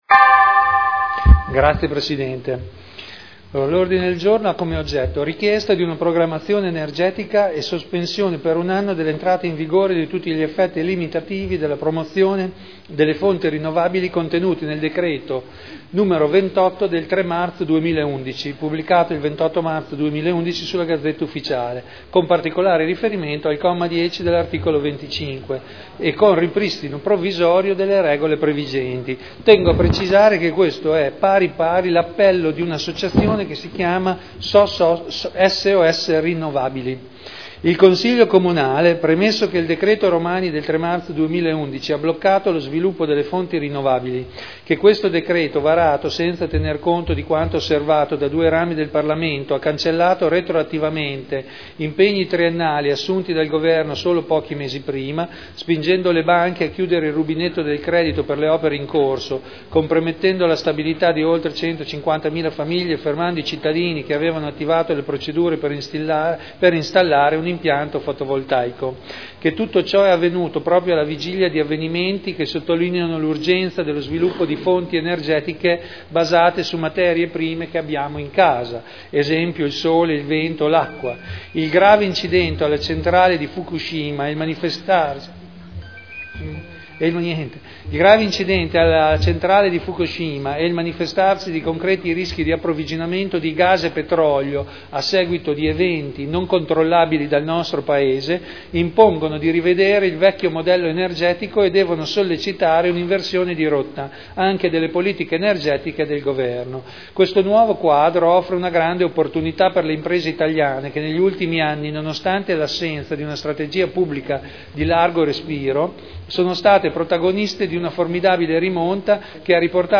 Vittorio Ballestrazzi — Sito Audio Consiglio Comunale
Seduta del 04/04/2011.